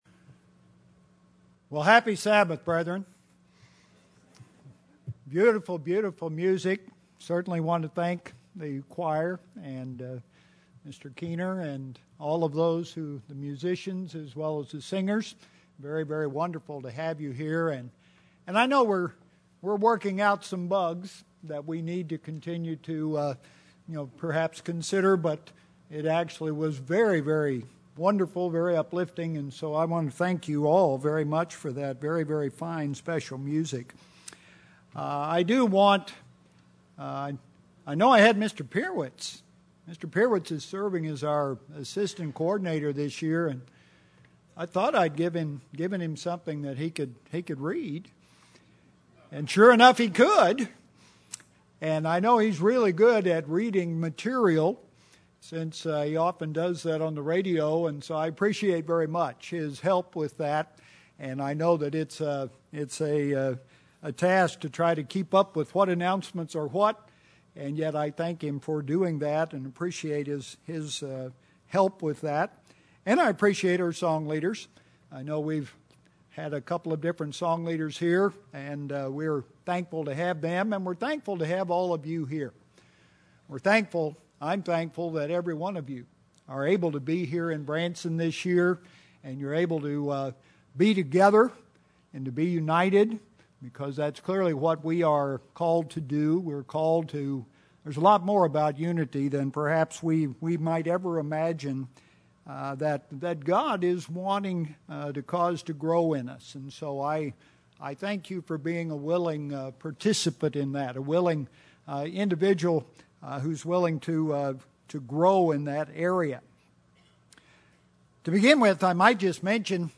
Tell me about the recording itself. This sermon was given at the Branson, Missouri 2013 Feast site.